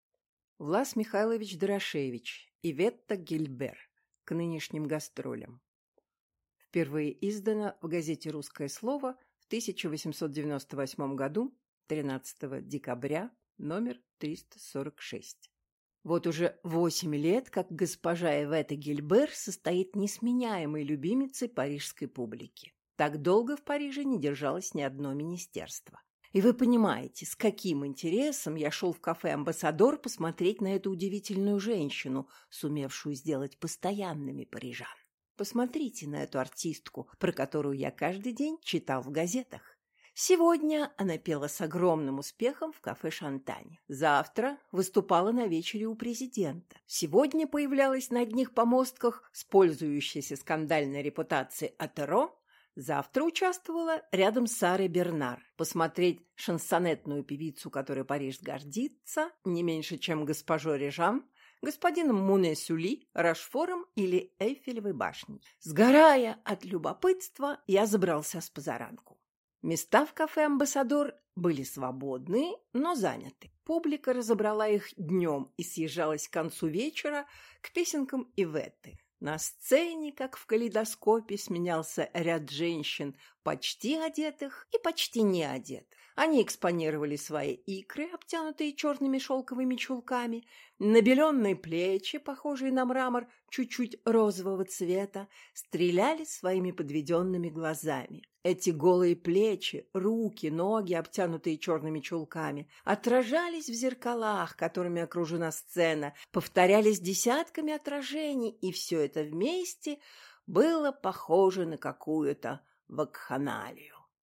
Аудиокнига Иветта Гильбер | Библиотека аудиокниг